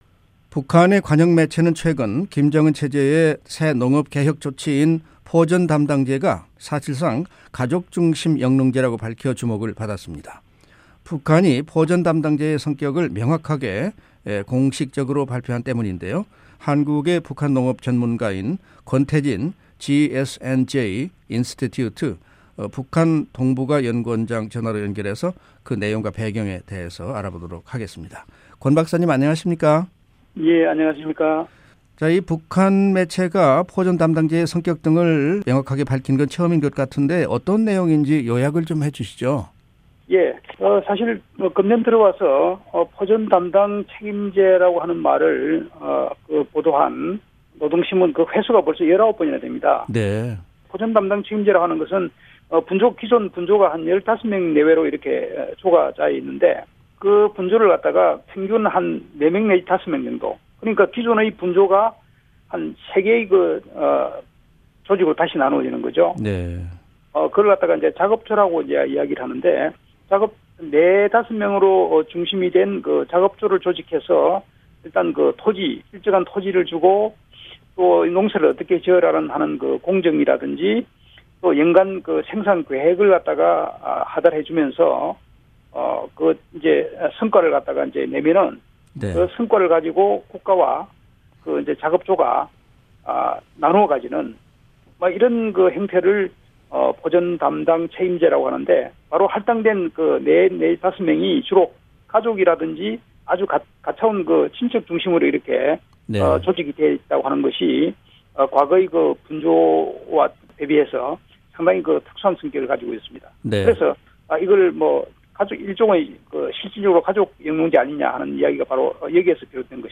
인터뷰